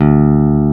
Index of /90_sSampleCDs/Roland - Rhythm Section/BS _Rock Bass/BS _Stretch Bass